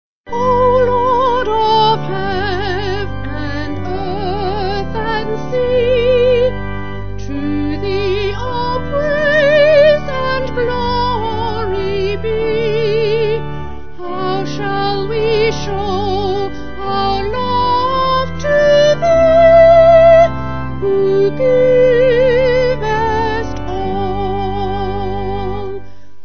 Vocals & Organ